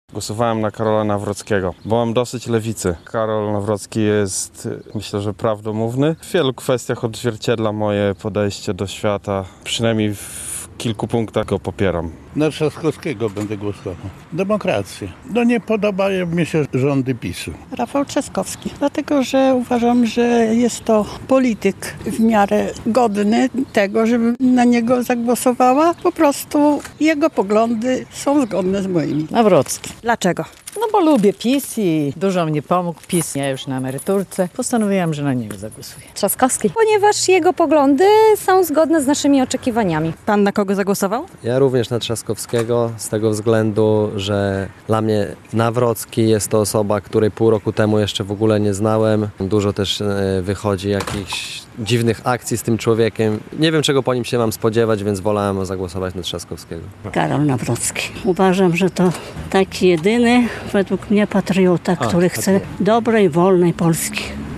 Na kogo głosowali mieszkańcy Suwałk? - relacja